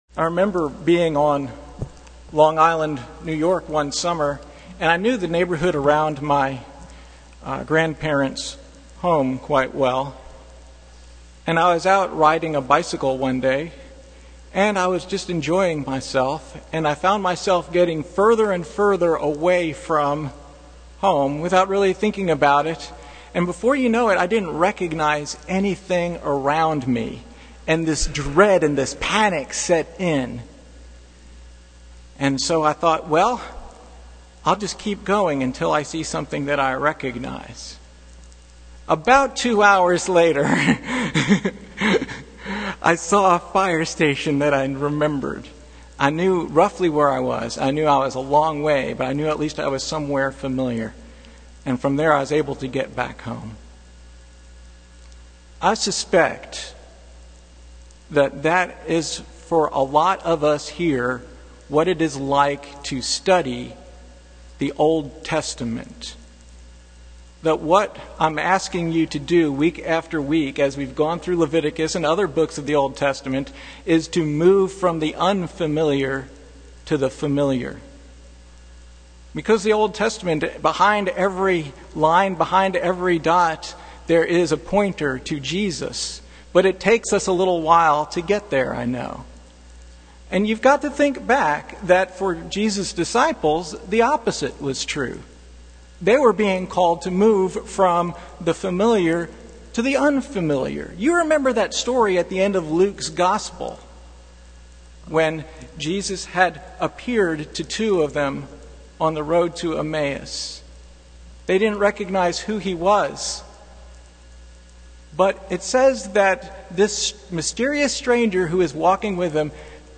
Passage: Leviticus 23:15-21 Service Type: Sunday Morning